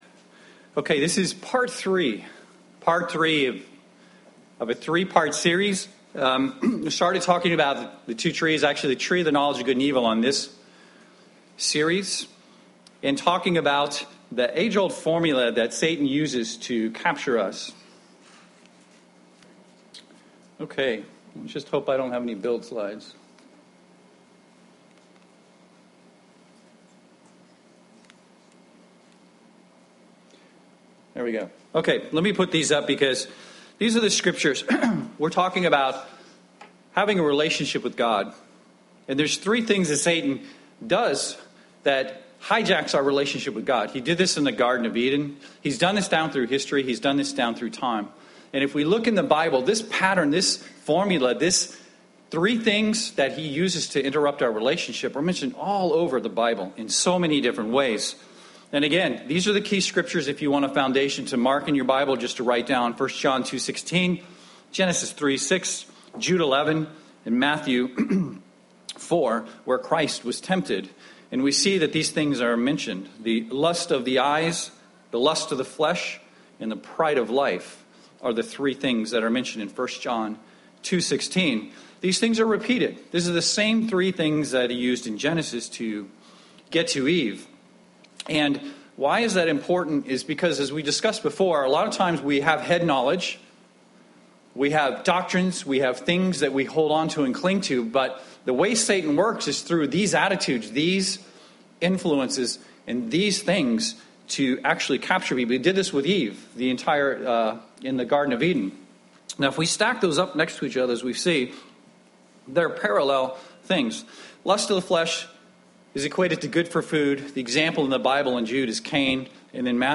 UCG Sermon lust sin Transcript This transcript was generated by AI and may contain errors.